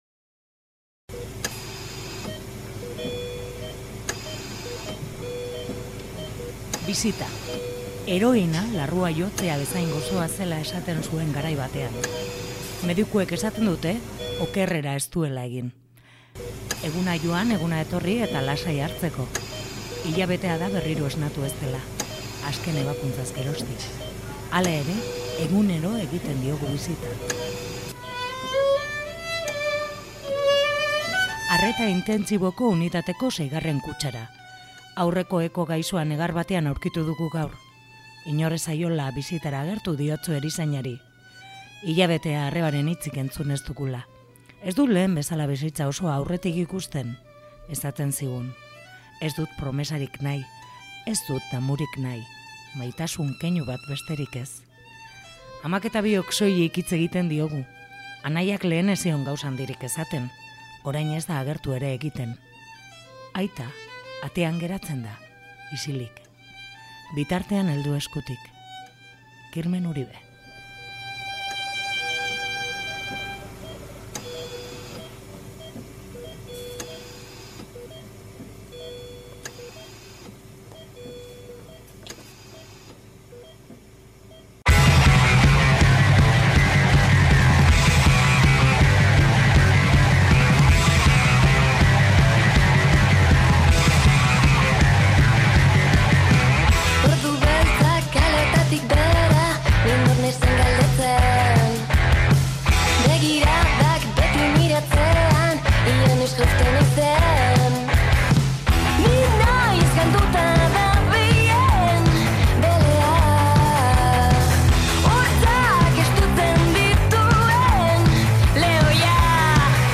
Hemen duzue entzungai berarekin izandakon solasaldia. https